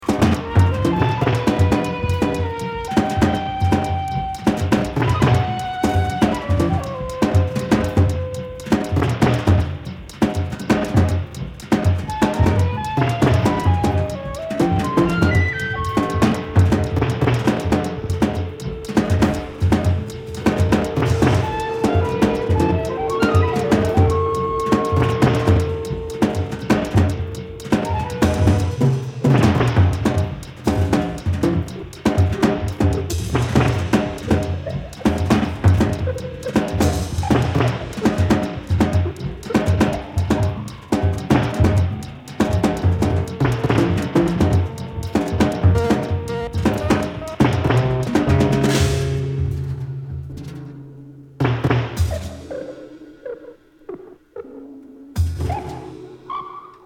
スリリングなアフロビート、リラクシンなジャズファンク、フリージャズ、マンボ